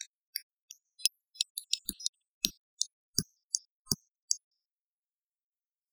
transient layer    transient layer
Glock_trans.wav